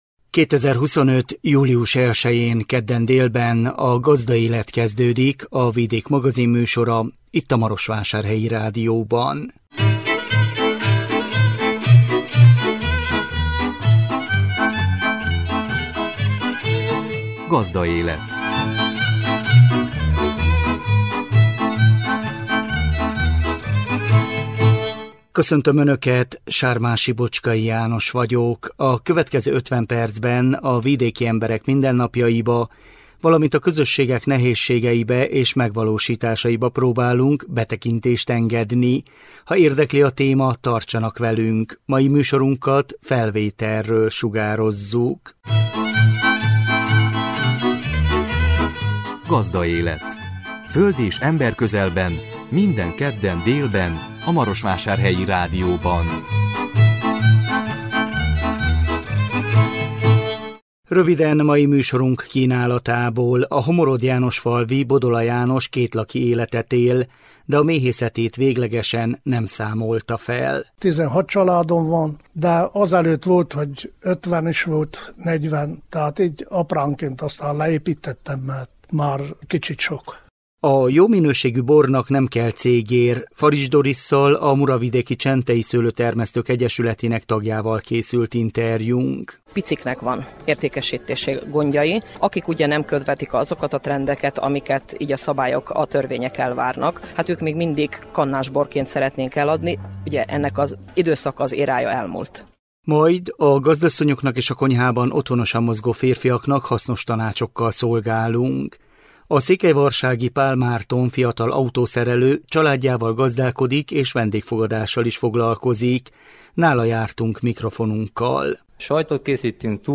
Nála jártunk mikrofonunkkal.